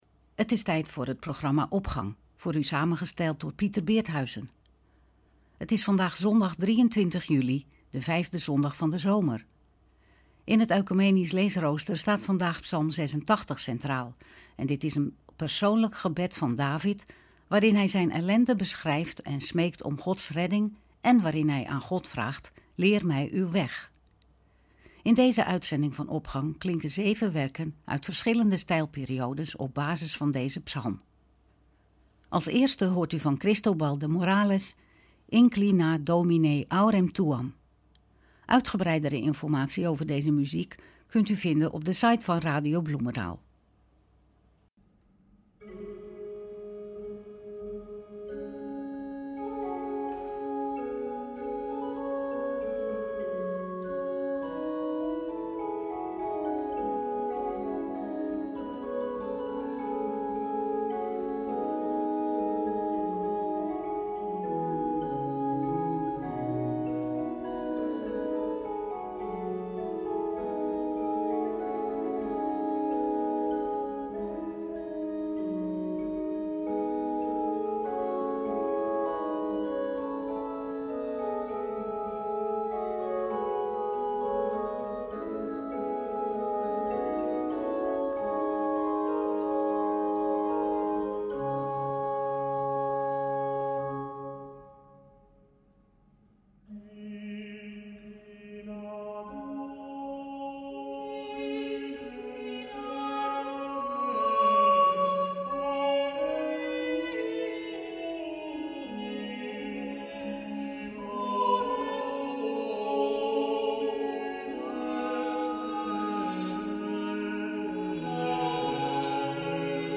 Opening van deze zondag met muziek, rechtstreeks vanuit onze studio.
In deze uitzending van Opgang klinken 7 werken uit verschillende stijlperiodes, op basis van deze psalm.